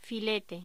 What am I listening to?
Locución: Filete voz